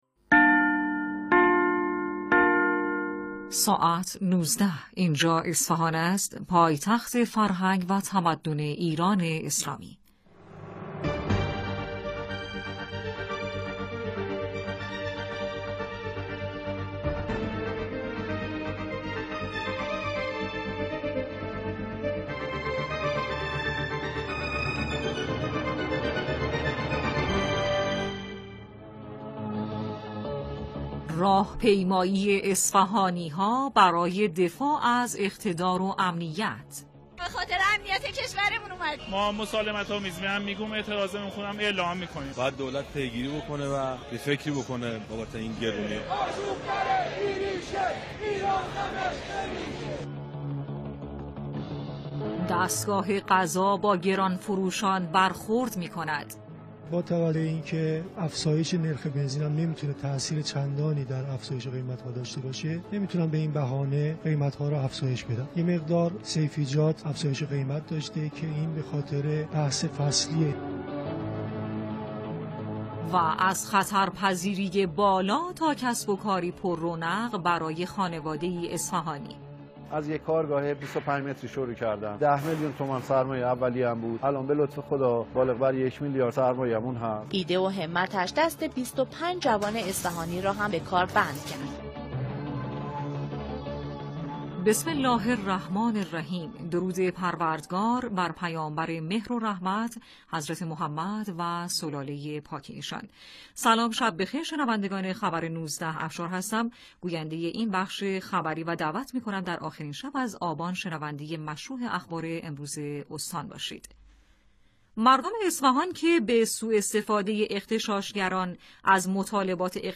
برخی از مهمترین رویداد‌ها و گزارش‌های خبری امروز را در اخبار 19 صدای مرکز اصفهان بشنوید.